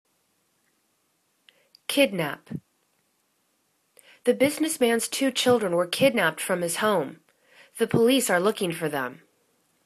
kid.nap     /'kidnap/    v